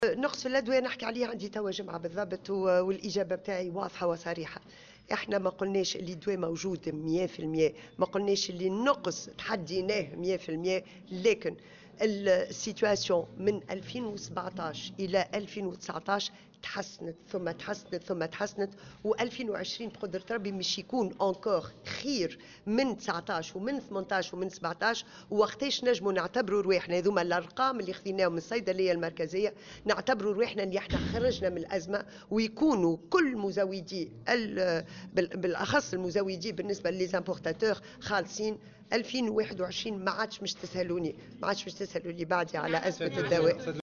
وأكدت في تصريح لمراسل "الجوهرة أف أم" على هامش زيارتها اليوم إلى عدد من المنشآت الصحية بولاية المنستير أنه سيتم تجاوز هذه الأزمة نهائيا مع أفق سنة 2021، وفق قولها.